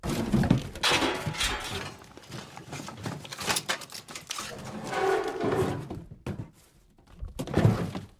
garbage_hard_1.ogg